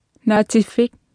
Martha tassaavoq kalaallisut qarasaasiakkut atuffassissut.